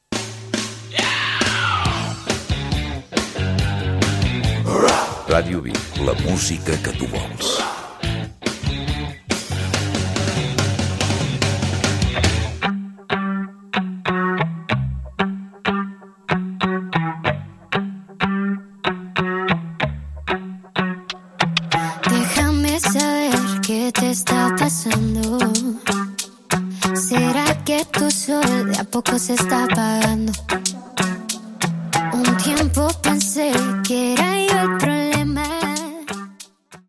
Indicatiu i tema musical